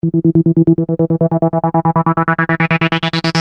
Free MP3 vintage Sequential circuits Pro-1 loops & sound effects 1